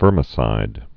(vûrmĭ-sīd)